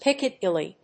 音節Pic・ca・dil・ly 発音記号・読み方
/pìkədíli(米国英語), ˈpɪkʌˌdɪli:(英国英語)/